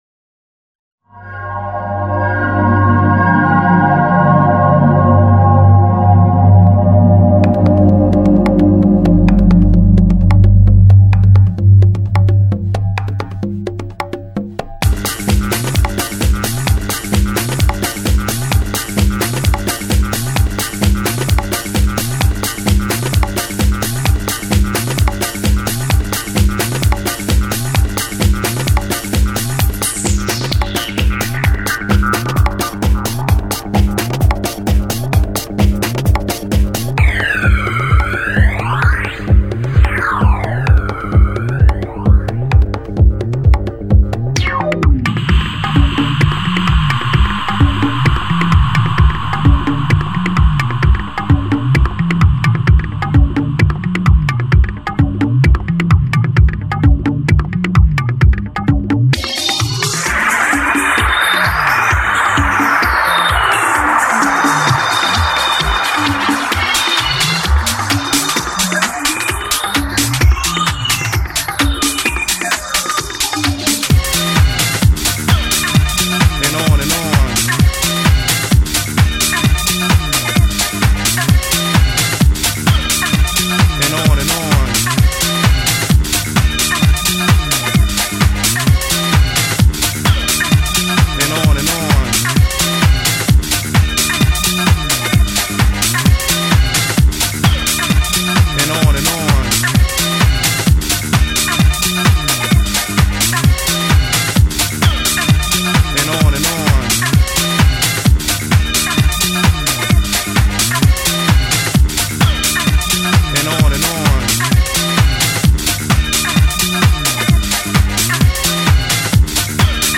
Vos Compos House
là j'ecoute newone c'est sympa mais c'est vrais que c'est un peu reptitif ca manque de changements j'aime pas trop la fin